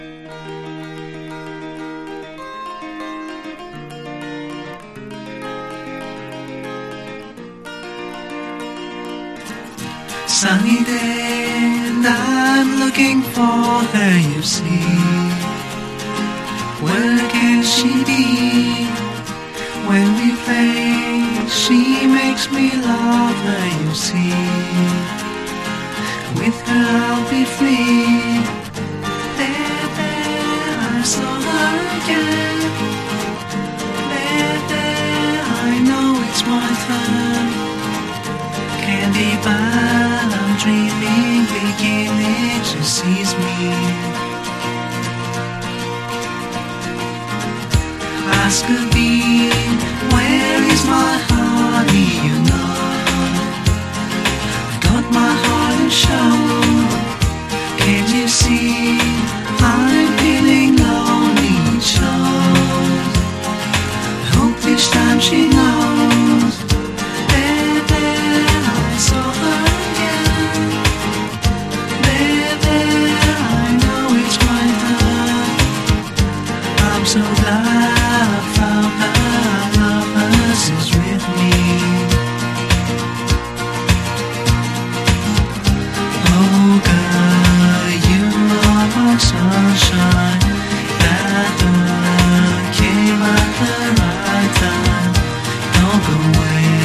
これぞエヴァー・グリーンなネオアコ金字塔！